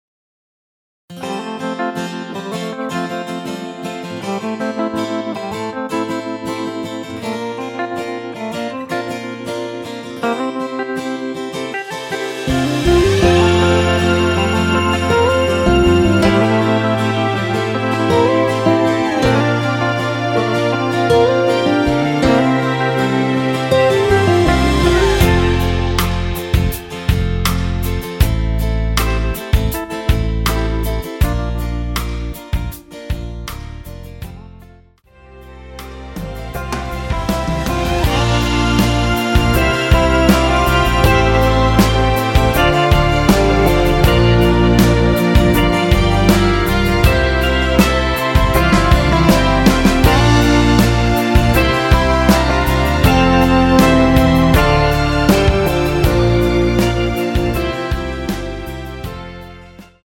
원키에서(+5)올린 MR입니다.(미리듣기 참조)
앞부분30초, 뒷부분30초씩 편집해서 올려 드리고 있습니다.
중간에 음이 끈어지고 다시 나오는 이유는